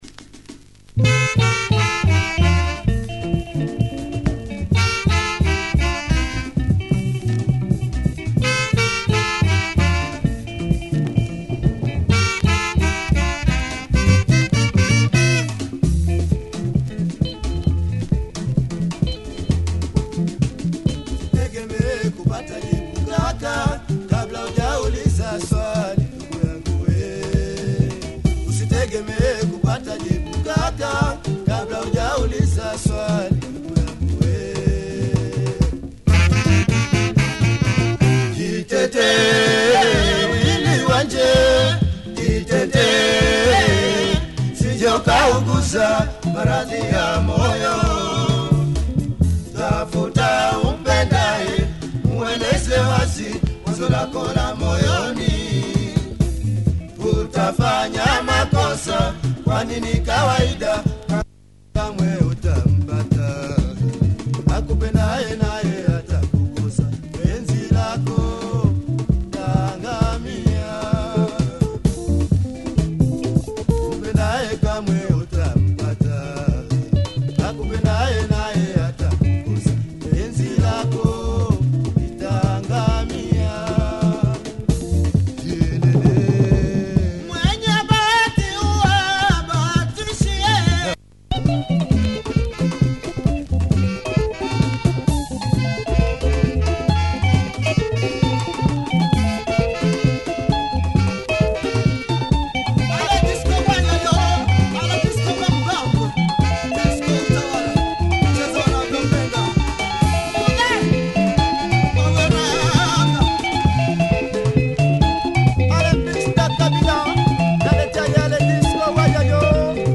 Solid Swahili groover by this little known Orchestre.